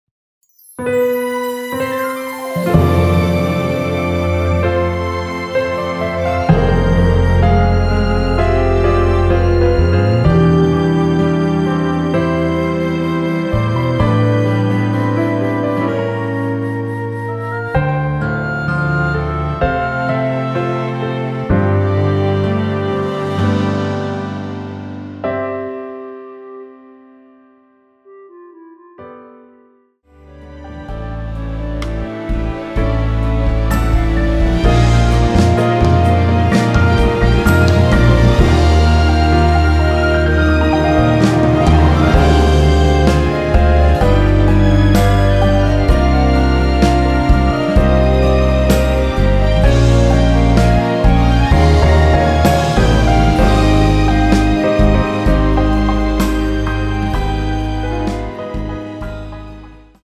원키 멜로디 포함된 MR 입니다.(미리듣기 참조)
멜로디 MR이라고 합니다.
앞부분30초, 뒷부분30초씩 편집해서 올려 드리고 있습니다.